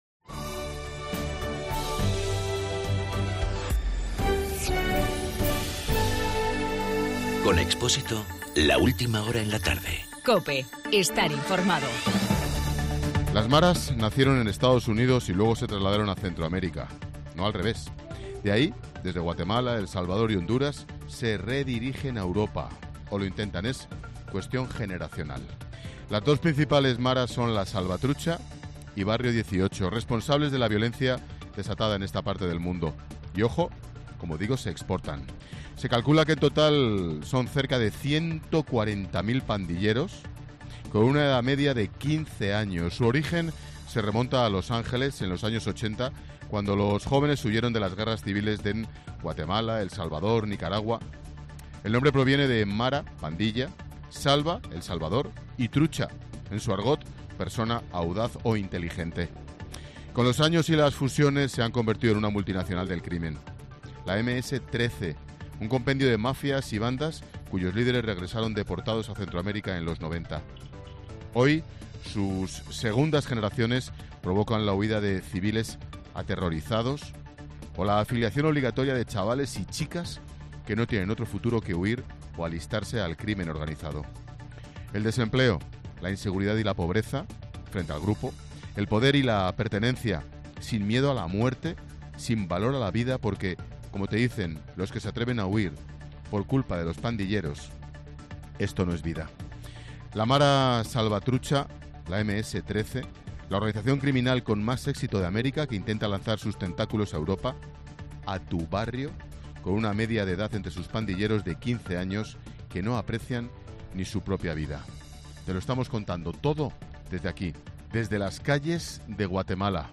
Ángel Expósito en su monólogo de las cinco, desde las calles de Venezuela.